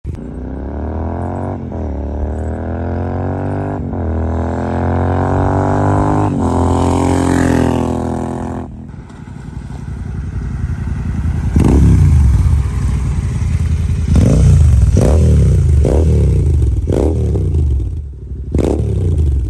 Venom Exhaust Flyby
• Sporty note with deep bass
Venom-Flyby.mp3